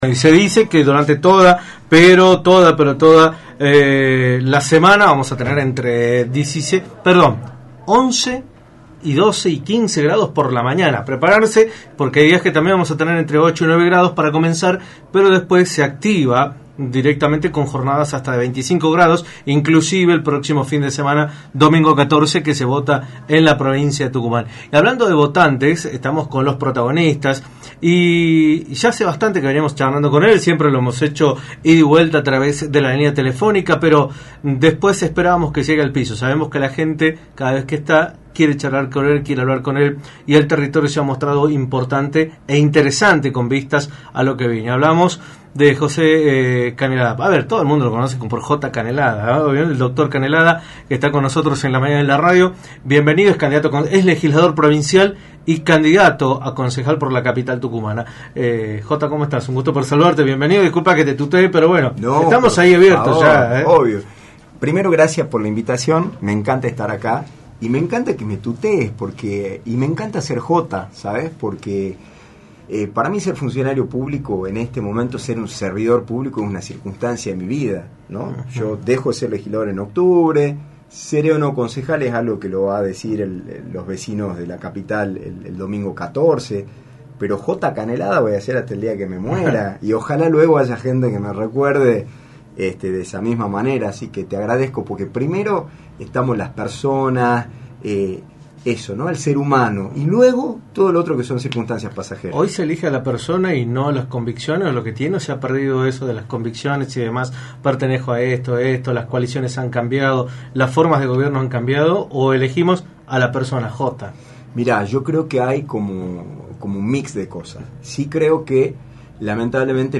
José María Canelada, Legislador y candidato a Concejal de San Miguel de Tucumán por Juntos por el Cambio, visitó los estudios de Radio del Plata, por la 93.9, para analizar el escenario político y electoral de la provincia, a menos de 7 días de las elecciones del próximo 14 de mayo.